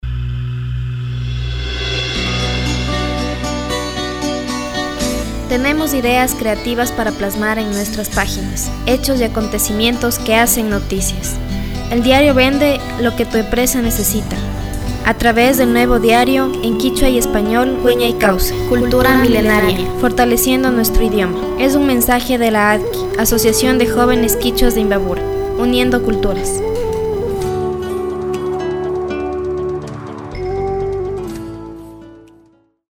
CUÑA DIARIO CULTURA.mp3